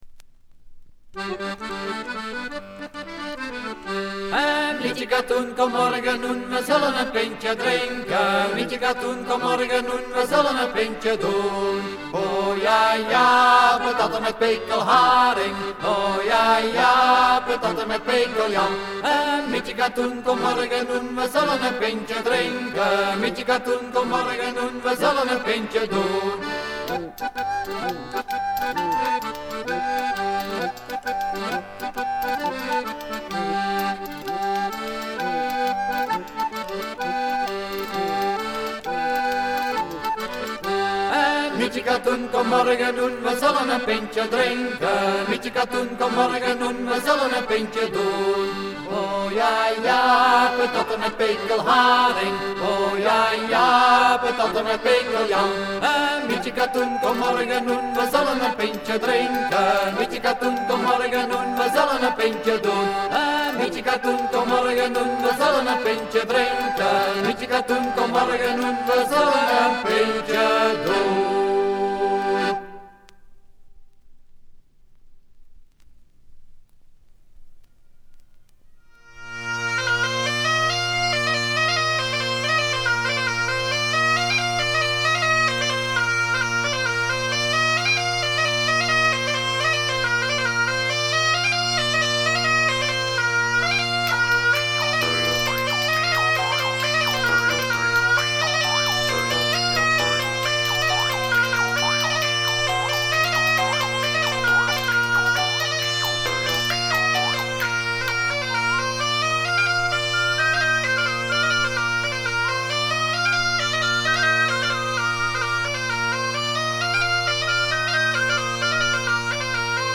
オランダのトラッド・バンド、男2女2の4人組。
ギターがないのがなんだか新鮮です。
試聴曲は現品からの取り込み音源です。